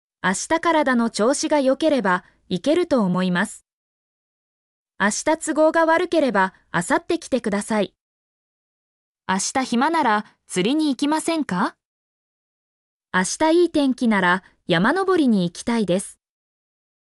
mp3-output-ttsfreedotcom-19_Wm8PJ2RD.mp3